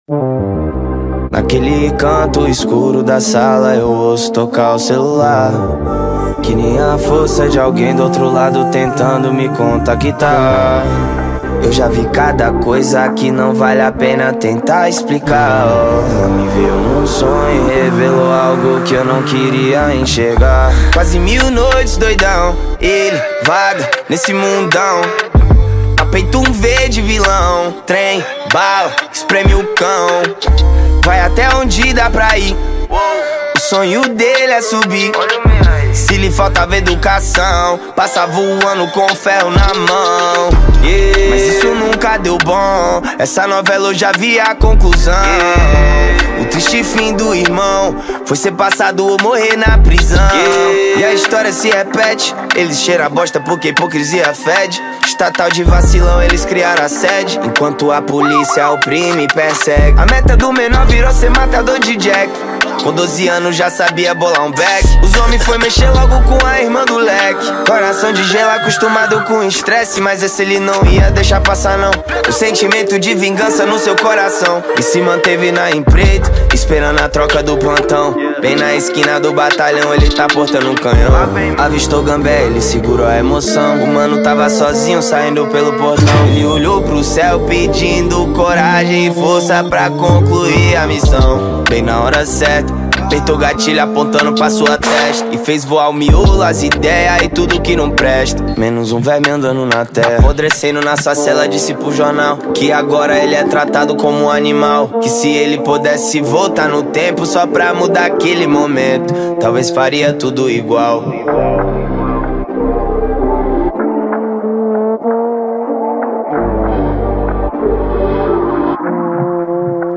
2024-09-16 00:01:21 Gênero: Trap Views